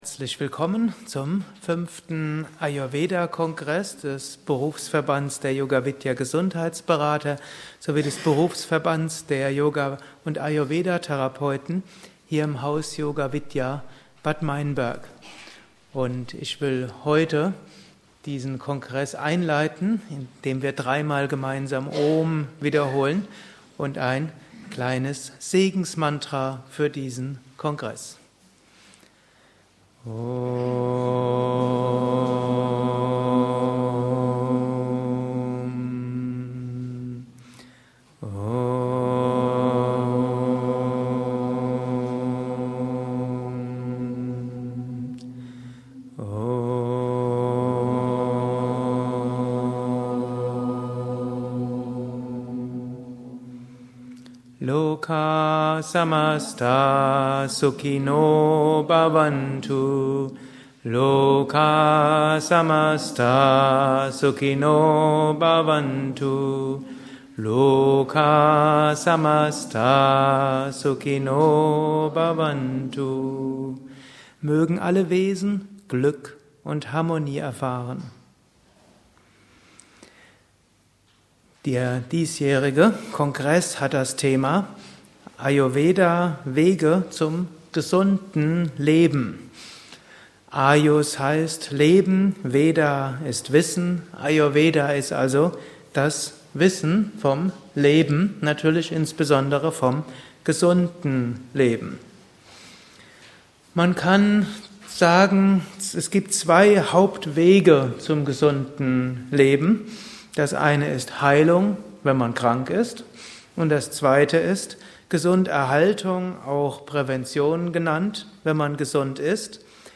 Eröffnungsvortrag - Ayurveda Kongress 2008 ~ Yoga Vidya Events Podcast